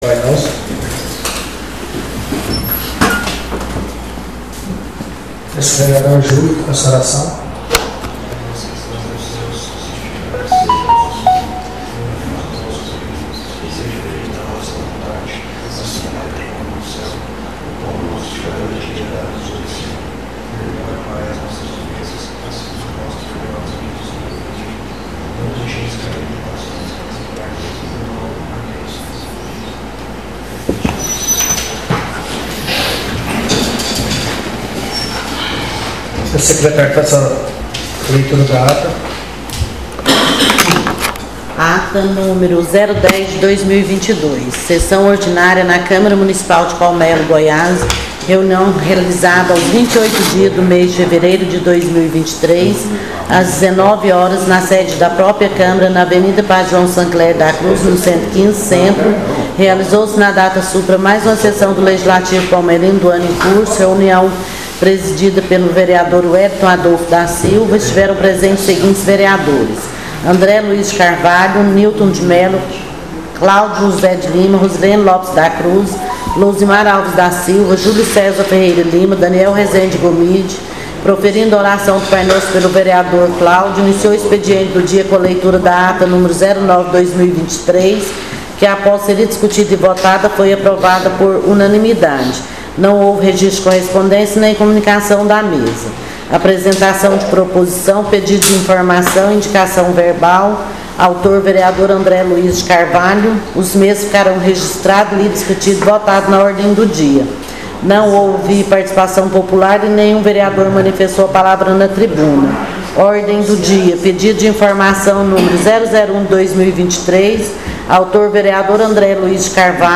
SESSÃO ORDINÁRIA DIA 07/03/2023